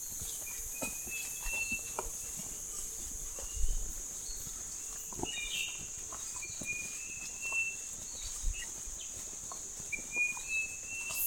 Illadopsis albipectus
Nombre en inglés: Scaly-breasted Illadopsis
Localidad o área protegida: Kibale National Park
Condición: Silvestre
Certeza: Vocalización Grabada
Illadopsis-albipectus-KIBALE.mp3